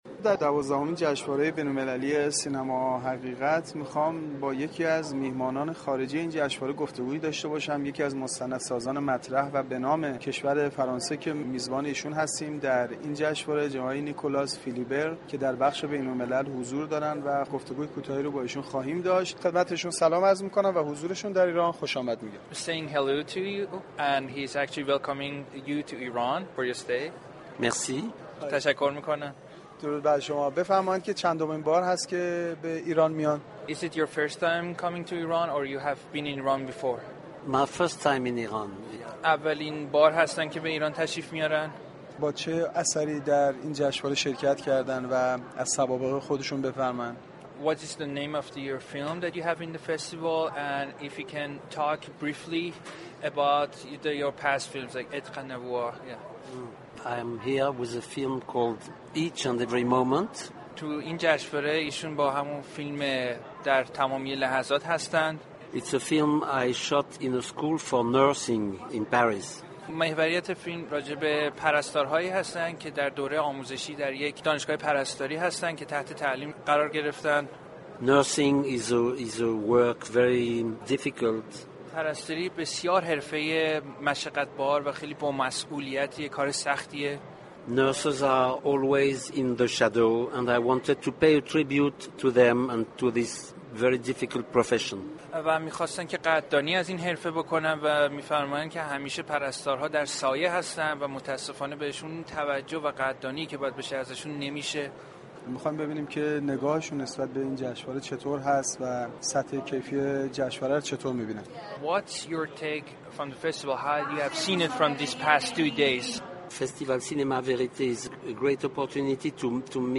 نیكلا فیلیبر در گفتگوی اختصاصی با گزارشگر رادیو فرهنگ گفت : برای اولین بار است به ایران سفر میكنم و برای حضور فیلم « در تمامی لحظات » در دوازدهمین جشنواره فیلم حقیقت به ایران آمده ام .